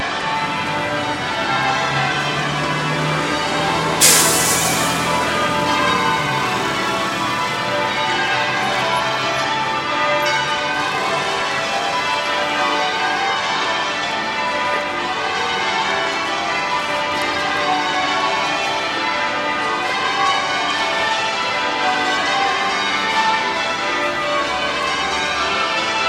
Bells of Westminster Abbey